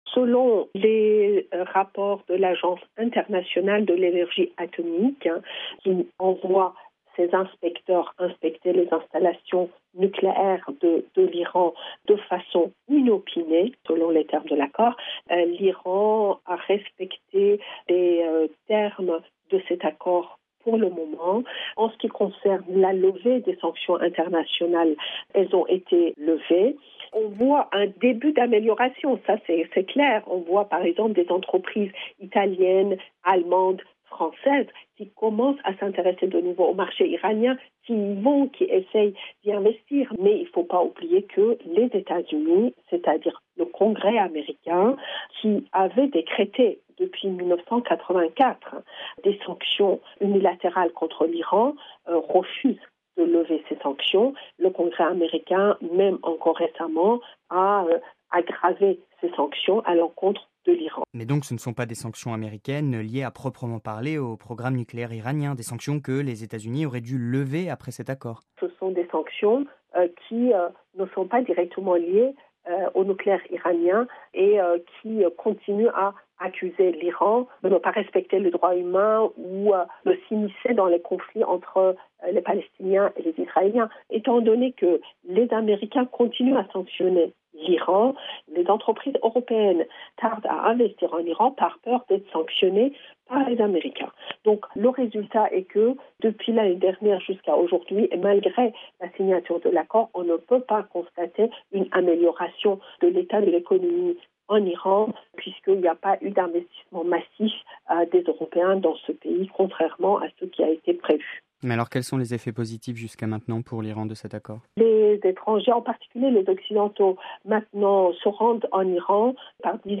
(RV) Entretien - Il y a un an, le mardi 14 juillet 2015, l’Iran signait avec les grandes puissances un accord sur le nucléaire. Le pays s’engageait à ne pas poursuivre son programme nucléaire militaire.